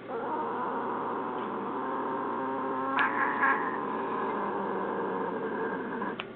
[audio] I hate claw trimming
Punkin Merrrr is general-purpose grumpy noise.
Punkin Plain, makes continuous noise during claw trimming.